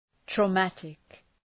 Προφορά
{trə’mætık} (Επίθετο) ● τραυματικός